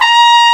Index of /m8-backup/M8/Samples/FAIRLIGHT CMI IIX/BRASS1